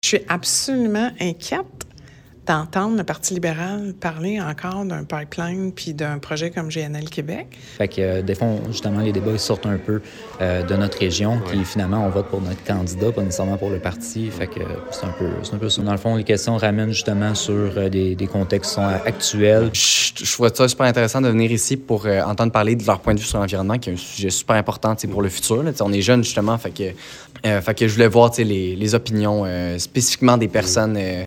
Voici quelques commentaires recueillis après le débat.